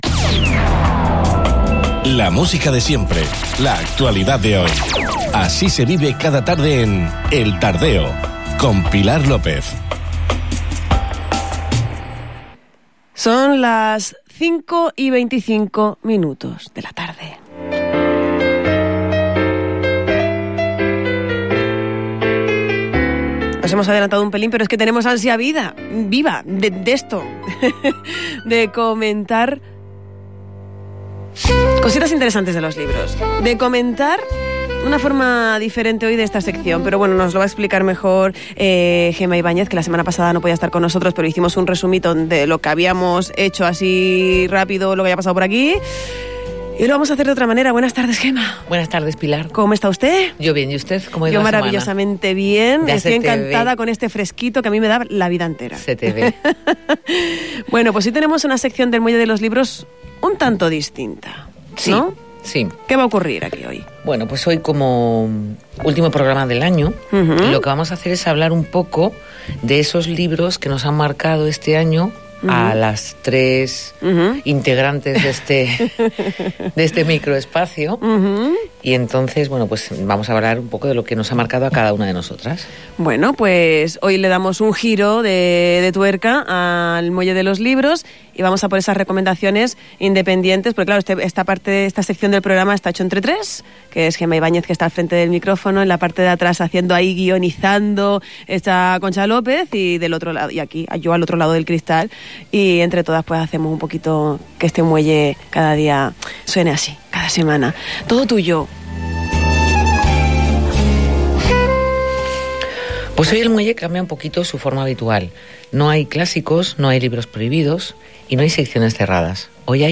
El espacio 'El Muelle de los Libros' dedica esta edición a las lecturas favoritas de las tres componentes que realizan este tiempo de radio, en el programa El